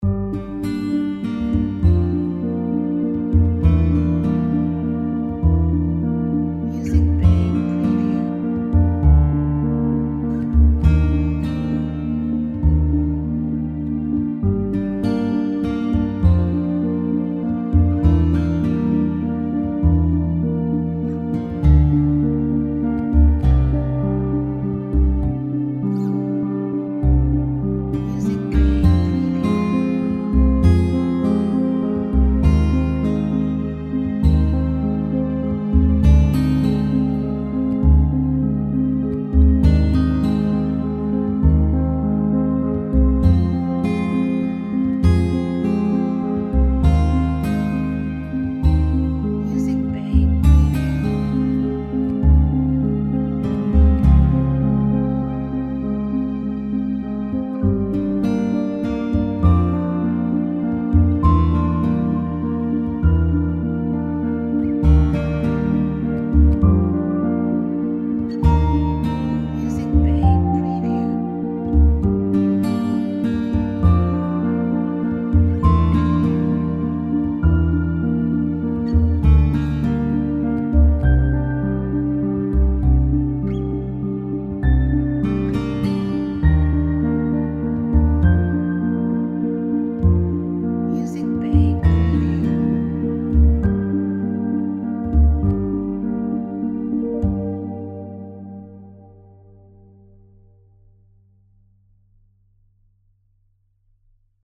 Dramatic music for slideshows.